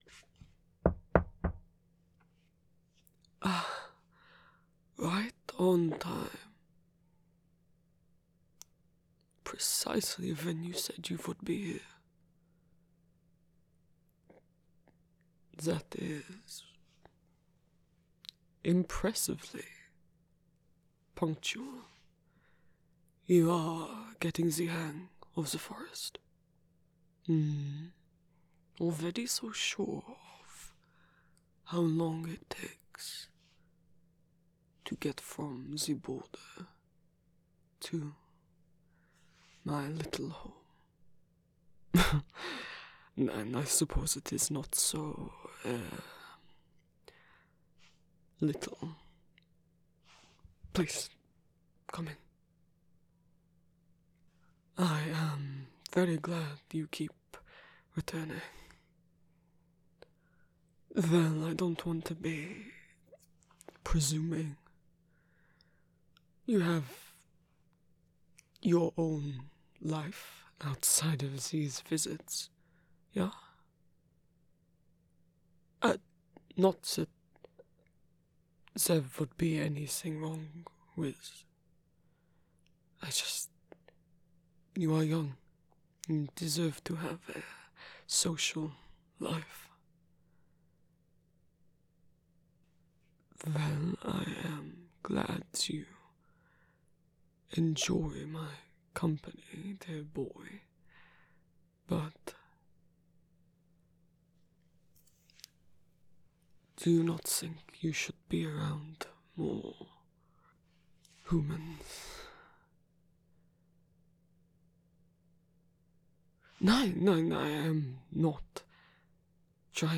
[M4M] [Flirting] [Sort of feelings confession] [First kiss] [More lore]